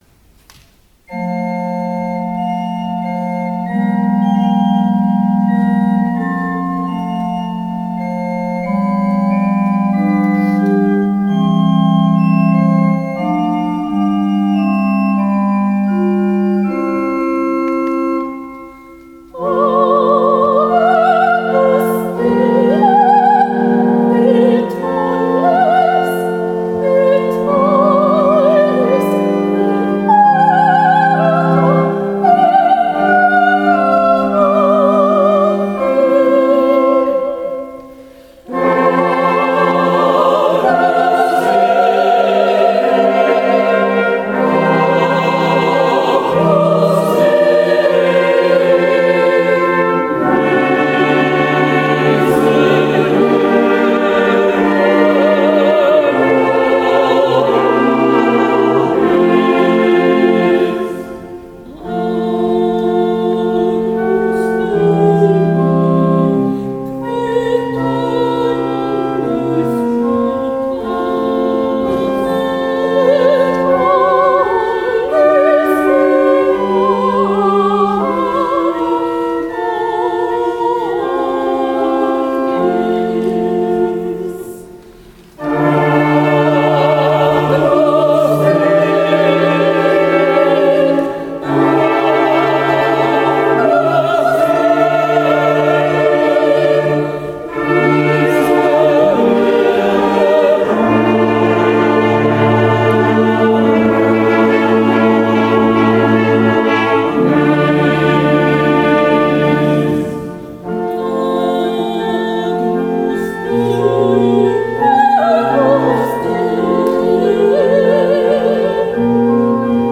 Gattung: Messe